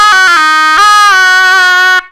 SHENNAI1  -L.wav